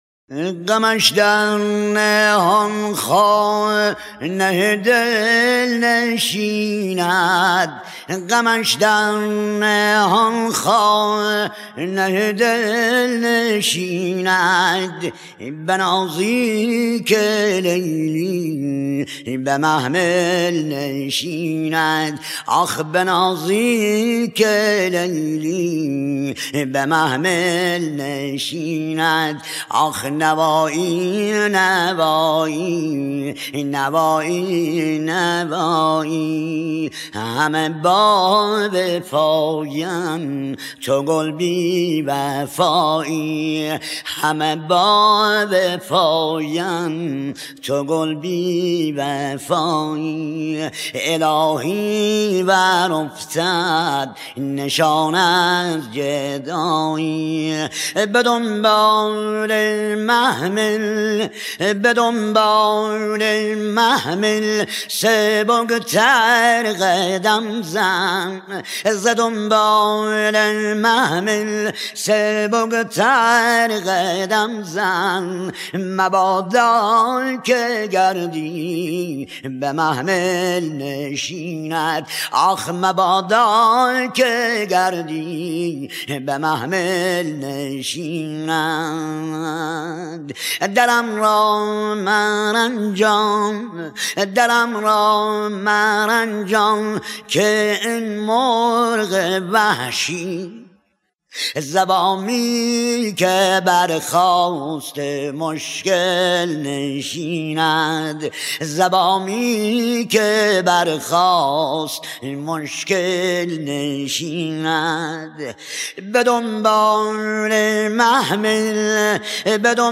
موسیقی مقامی
دوتار